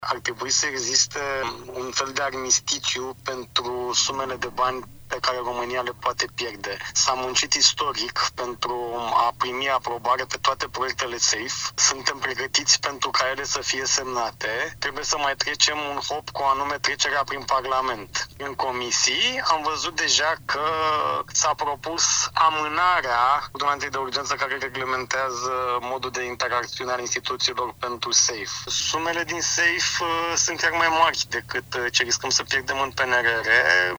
Riscul de a pierde banii pentru înzestrarea Armatei, prin Programul SAFE finanțat de Uniunea Europeană, există în contextul actualei crize politice – atrage atenția ministrul Apărării, Radu Miruță. El spune la Europa FM că intenționează să ceară în Parlament un armistițiu pentru votarea celor 21 de proiecte, în valoare de 17 miliarde de euro.